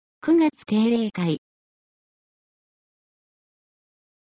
なお、この音声は「音訳グループまつさか＜外部リンク＞」の皆さんの協力で作成しています。